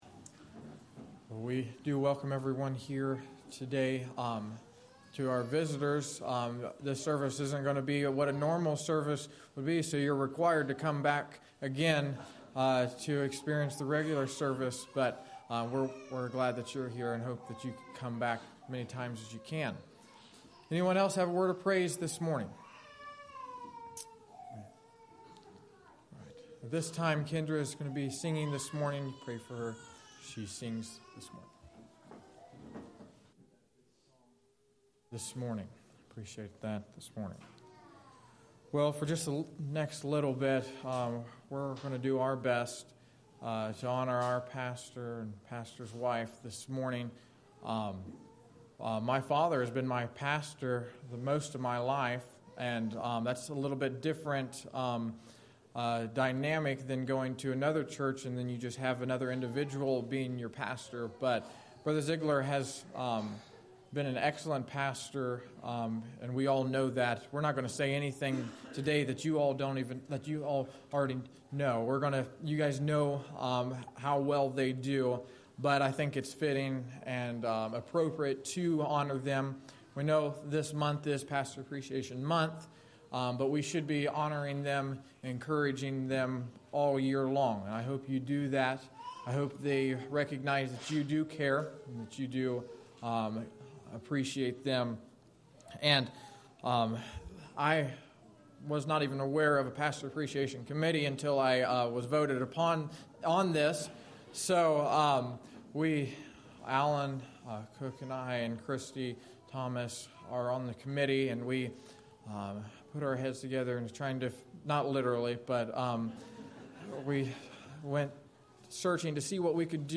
Save Audio A special service